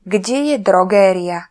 Slovak voice announciation